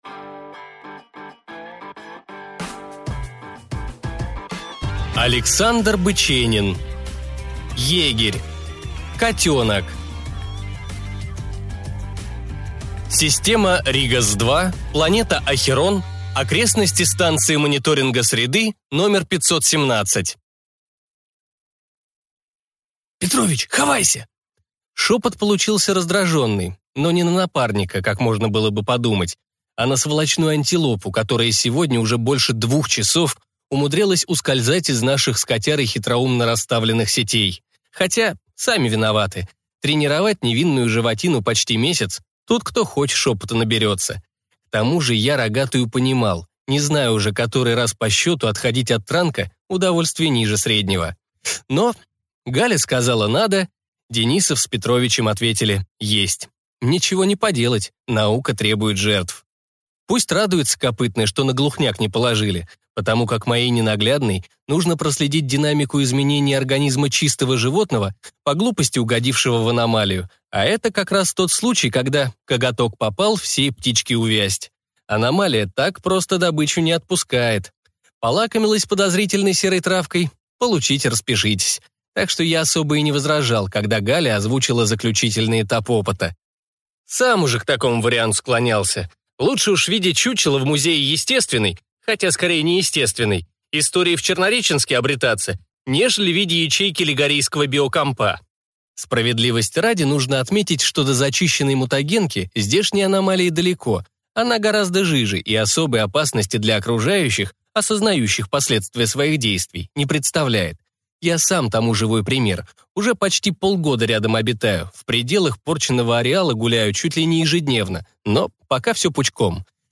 Аудиокнига Егерь. Котёнок | Библиотека аудиокниг